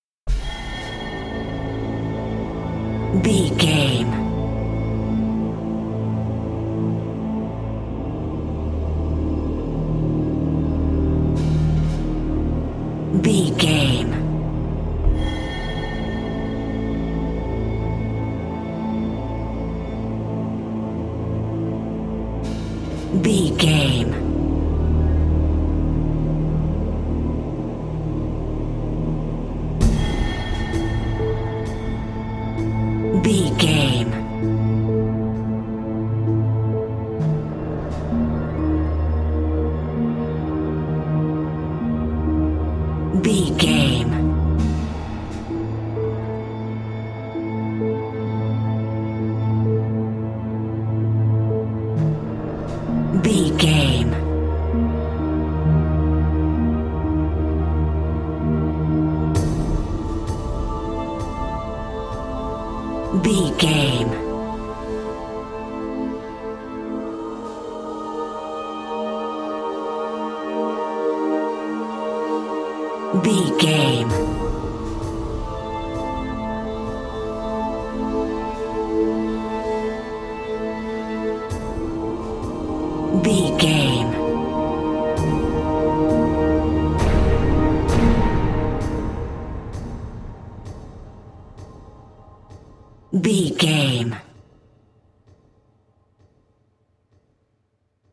Survival horror
Aeolian/Minor
piano
synthesiser
drums
harp
ominous
suspense
haunting
tense
creepy